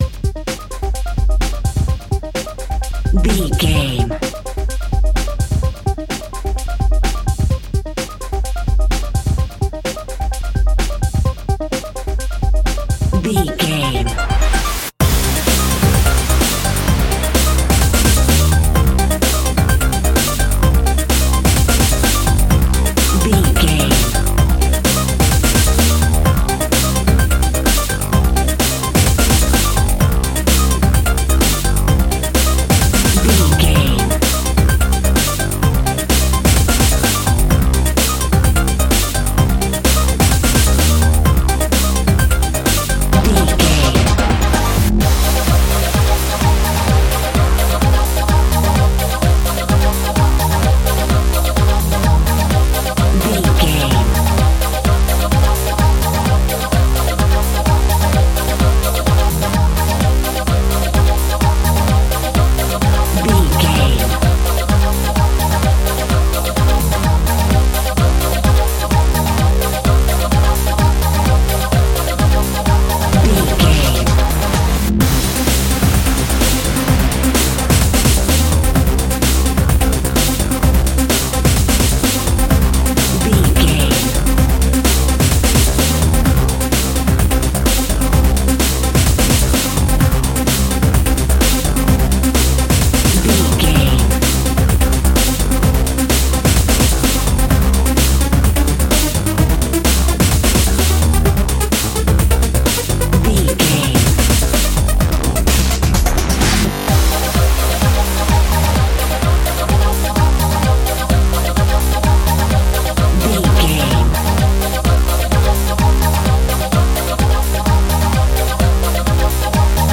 Powerful and Big Techno Trance.
Epic / Action
Fast paced
Aeolian/Minor
aggressive
dark
uplifting
driving
energetic
intense
drums
synthesiser
drum machine
acid house
electronic
uptempo
synth leads
synth bass